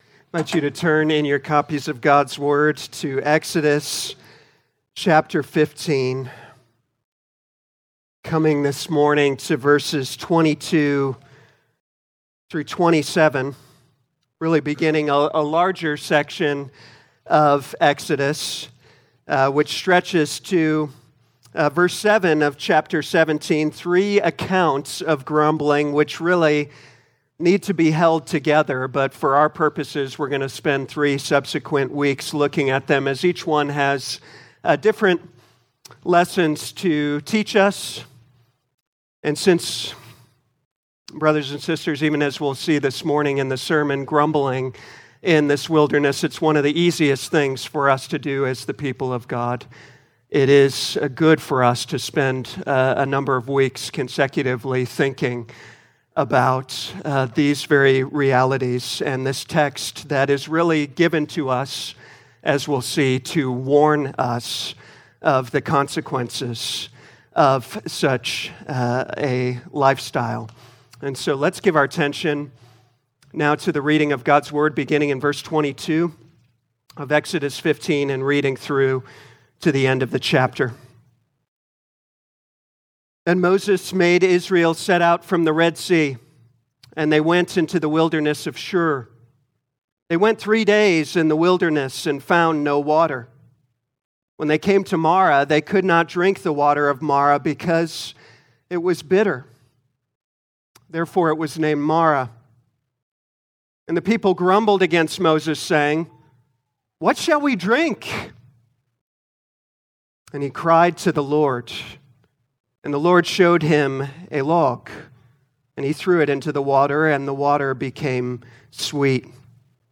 Audio Notes Bulletin All sermons are copyright by this church or the speaker indicated.